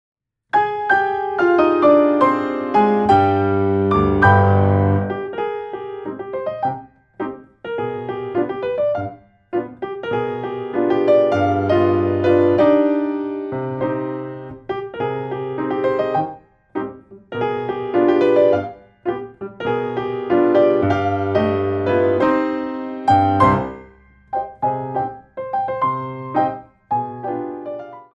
2 bar intro 4/4
32 bars